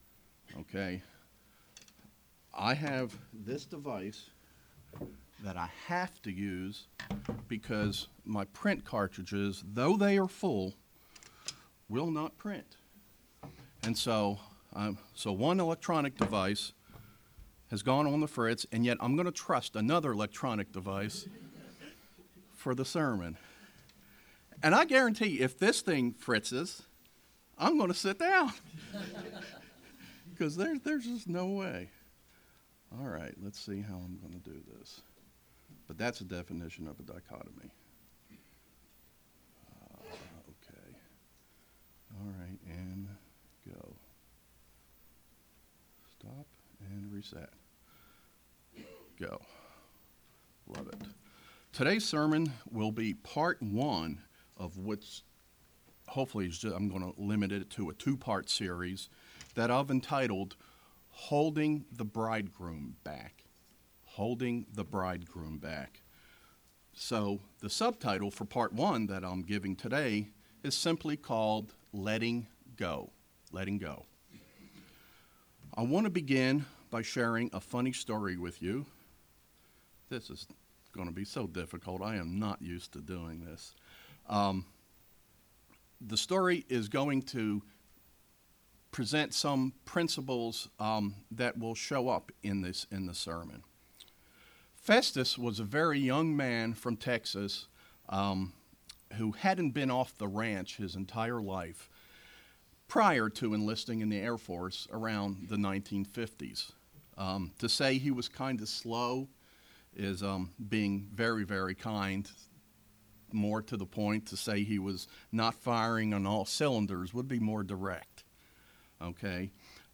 Sermons
Given in Knoxville, TN